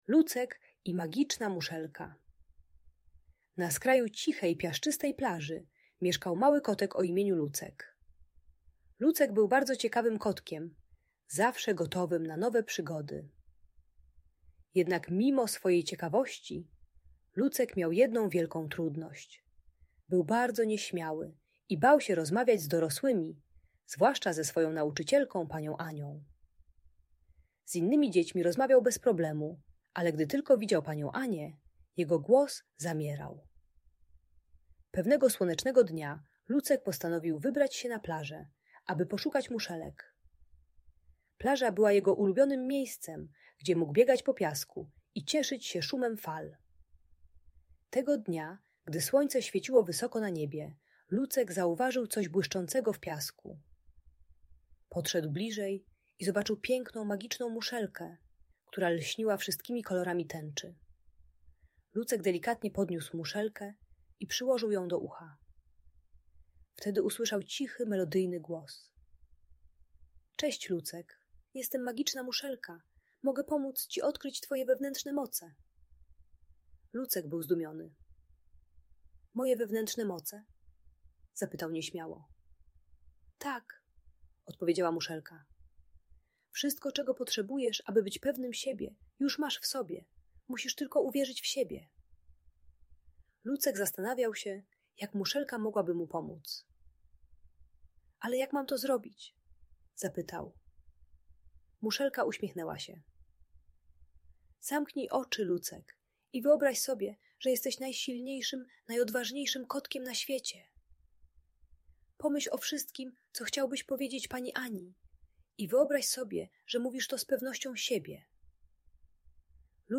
Lucek i Magiczna Muszelka - Lęk wycofanie | Audiobajka